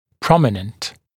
[‘prɔmɪnənt][‘проминэнт]выступающий, торчащий